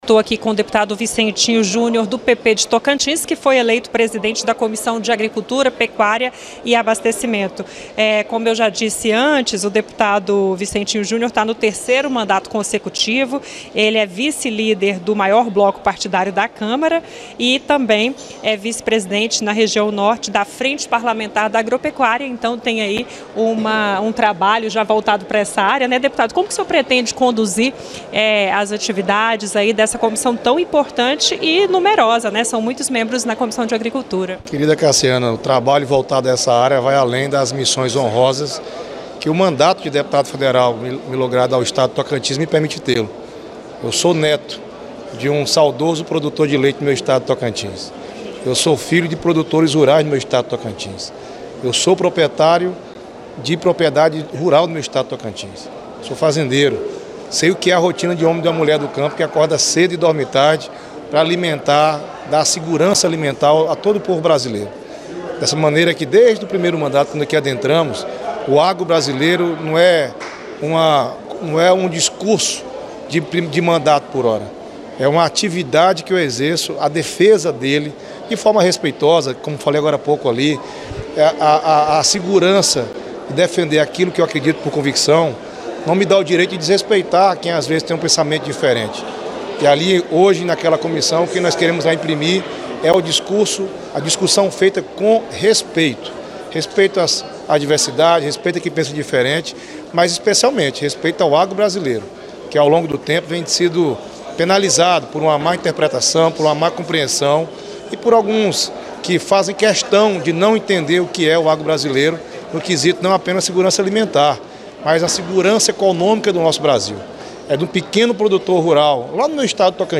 entrevista-dep-vicentinho-junior.mp3